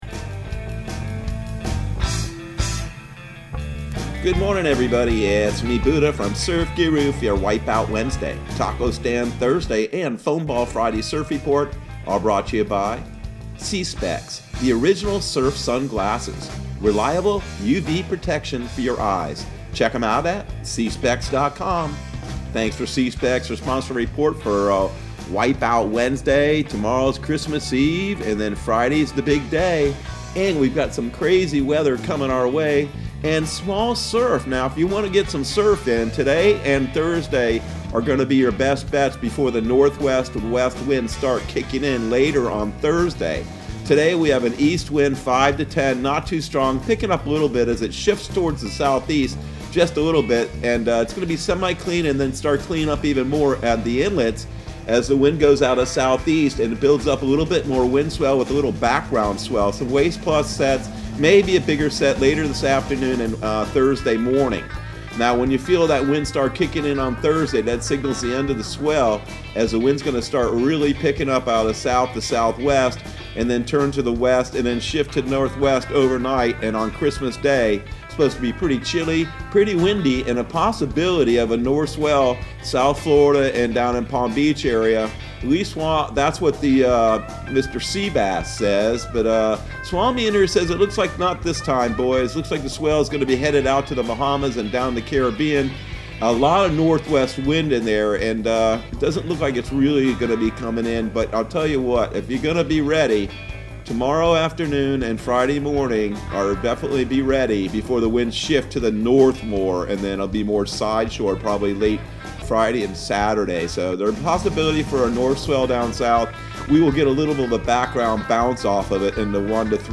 Surf Guru Surf Report and Forecast 12/23/2020 Audio surf report and surf forecast on December 23 for Central Florida and the Southeast.